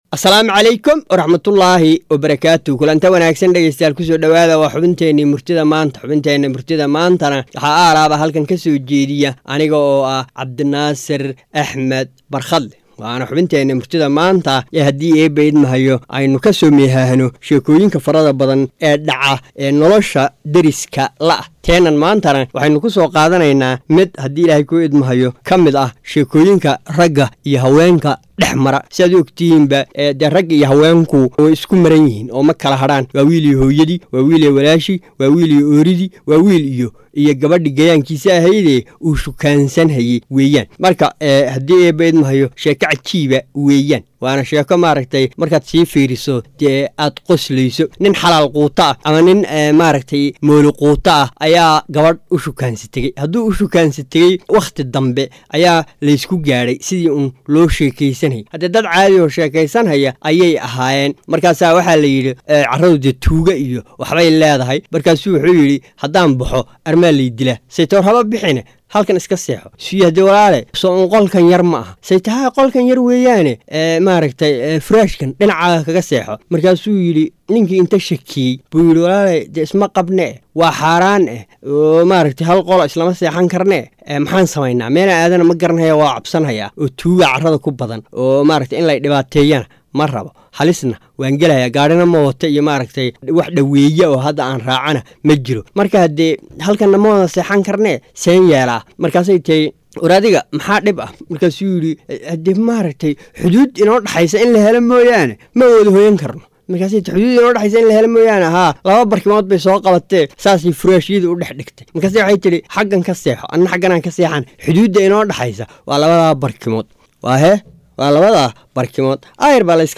Soo jeedinta abwaan